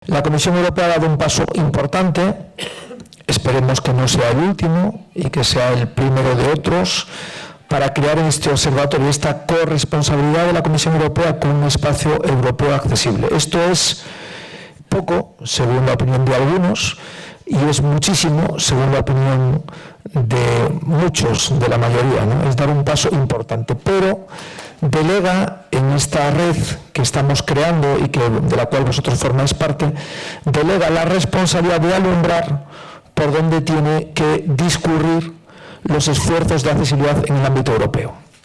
Fundación ONCE acogió, el pasado 7 de noviembre, un encuentro organizado por ‘AccessibleEU’ para impulsar el diálogo entre industria, administraciones públicas y sociedad civil con el objetivo de mejorar la accesibilidad.